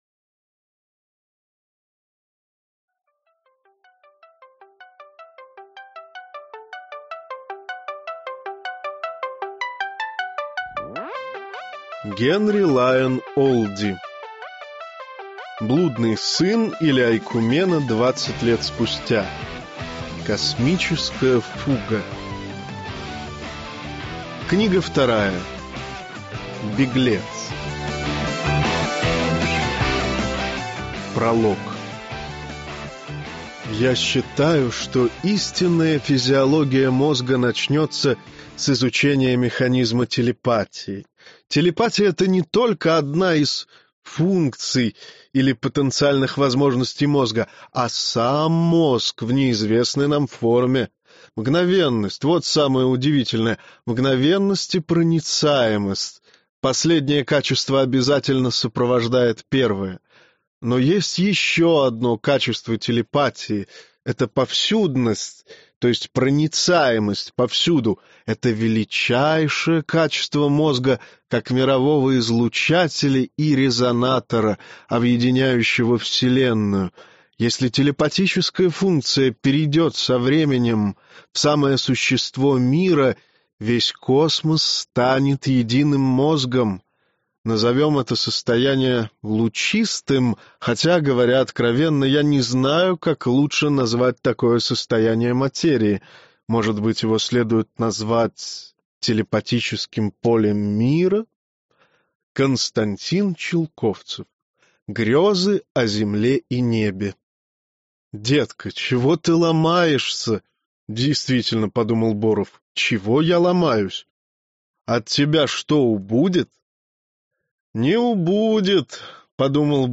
Аудиокнига Беглец - купить, скачать и слушать онлайн | КнигоПоиск